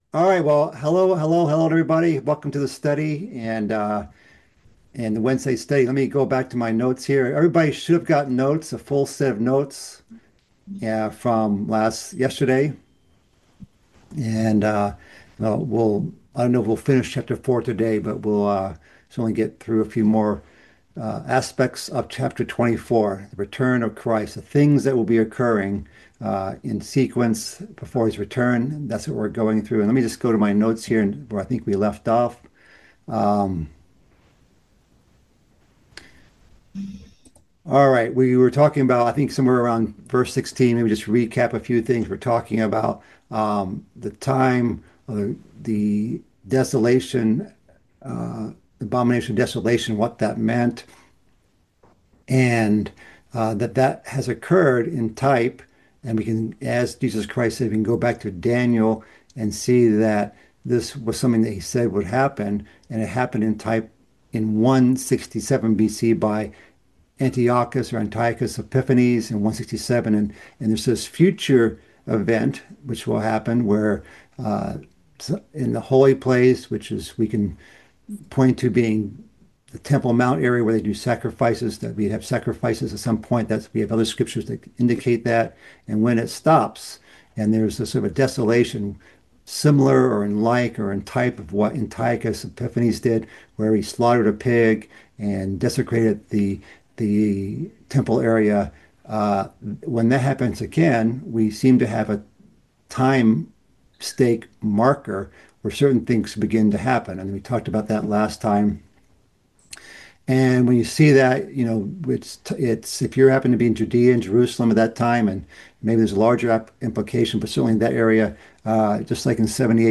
Bible Study - Fifth Discourse Part 9 - Matthew 24:23-31